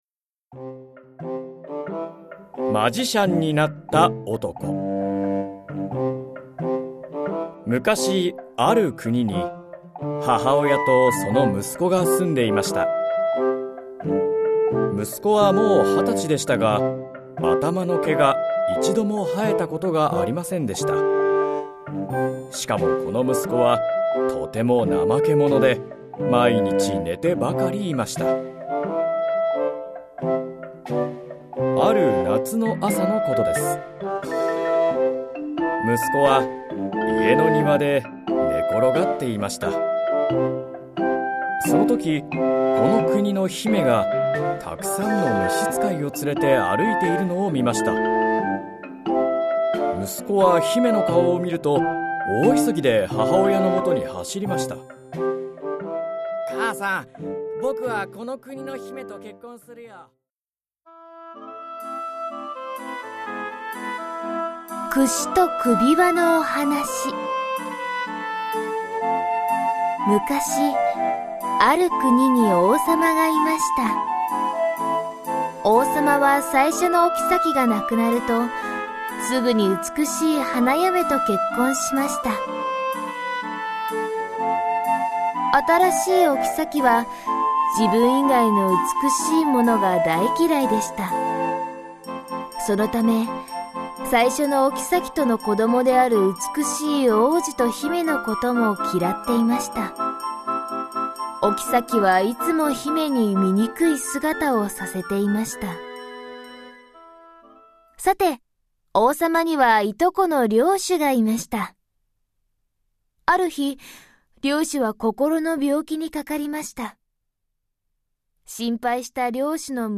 子どもの想像力を豊かに育む 昔話とファンタジーの読み聞かせオーディオブック
プロとして活躍する朗読家や声優、ナレーター達が感情豊かに読み上げます。